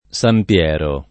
Sam pL$ro] top. — es.: San Piero Scheraggio [Sam pL$ro Sker#JJo] (chiesa di Firenze medievale), San Piero a Ponti [Sam pL$ro a pp1nti] (Tosc.), San Piero in Bagno [